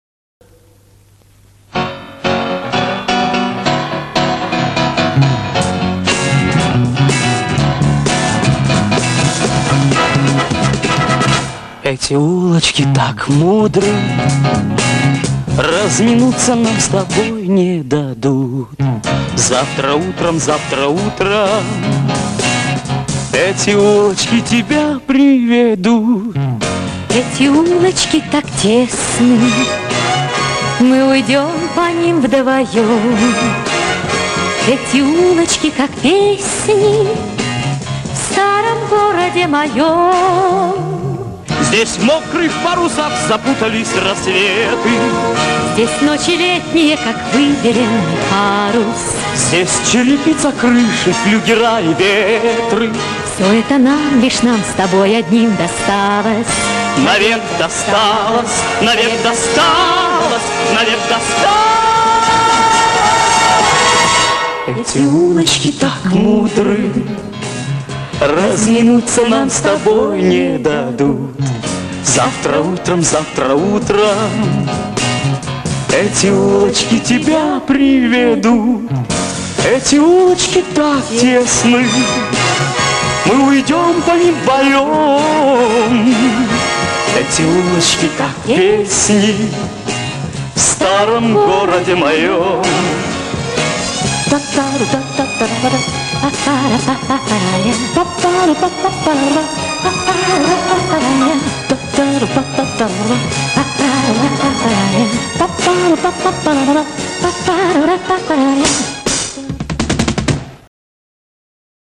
pesnya-o-rige---duet.mp3